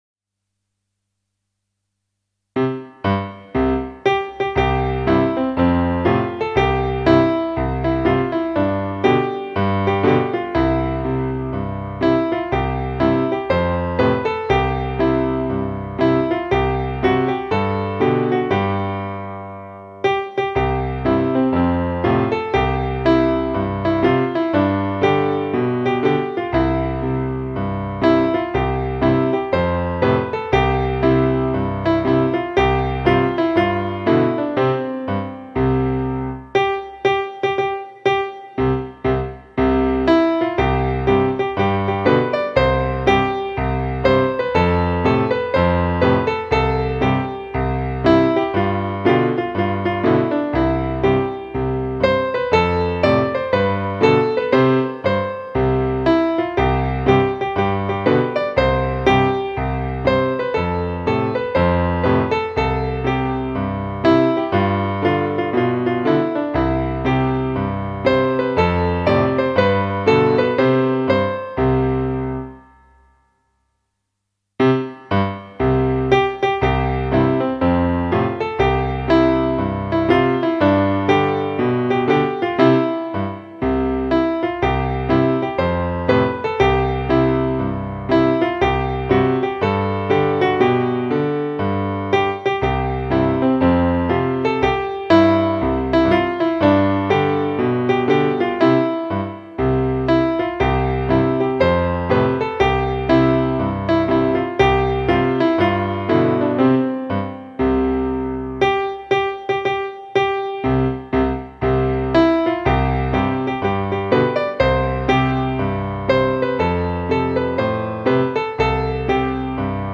>>> podkład do hymnu (pianino)
hymn_szkoly_podstawowej_w_pomiechowku__podklad_mp3.mp3